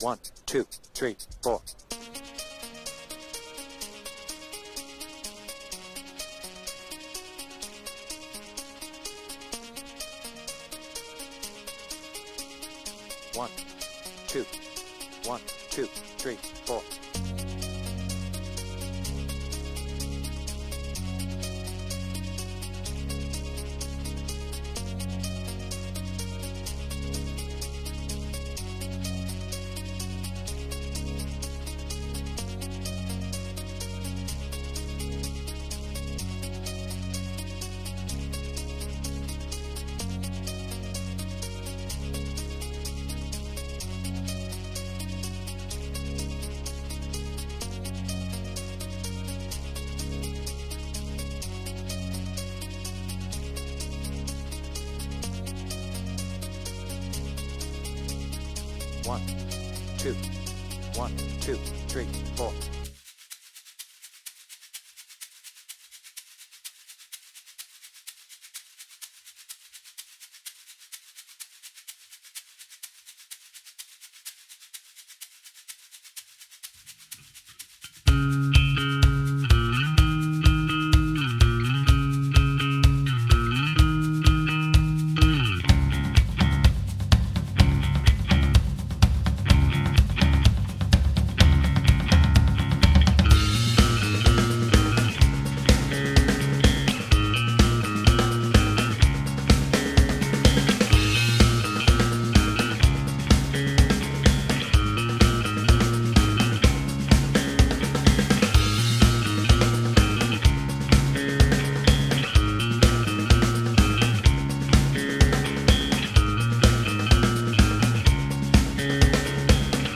BPM : 126
Without vocals